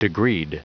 Prononciation du mot degreed en anglais (fichier audio)
Prononciation du mot : degreed